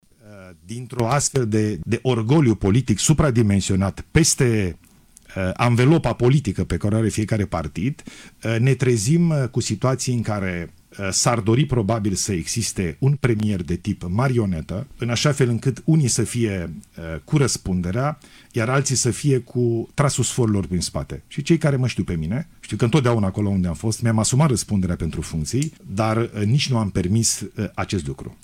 Acesta a fost întrebat într-un interviu la postul public de radio dacă va renunța la funcție în cazul în care PSD va decide prin vot să-i retragă sprijinul.